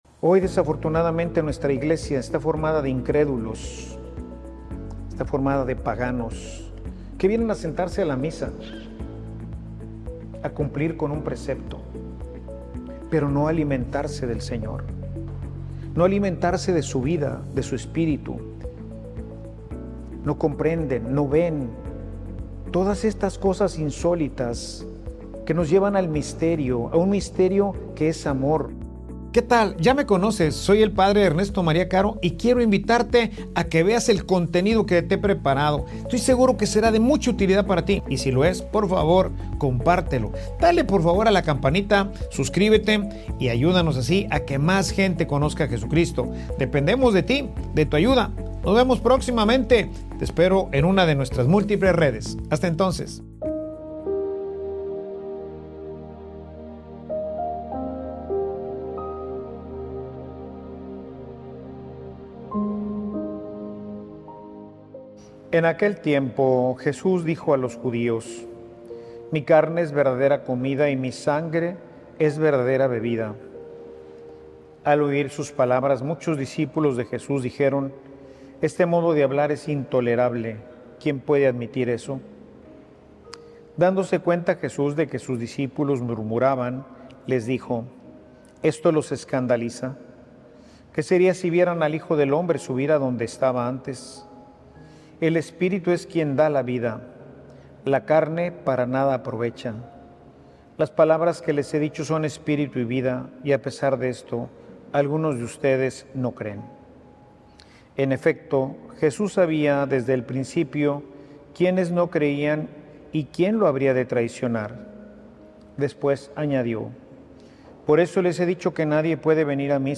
Homilia_Un_misterio_mas_alla_de_tus_sentidos.mp3